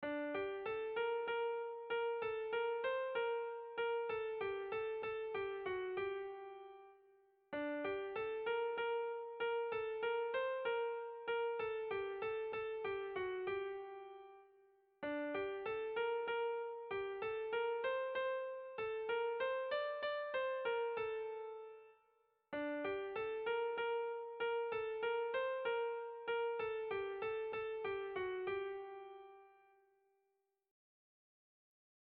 Erlijiozkoa
Gipuzkoa < Euskal Herria
AABA